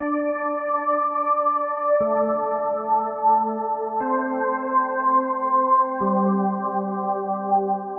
标签： 163 bpm Trap Loops Pad Loops 1.27 MB wav Key : C
声道立体声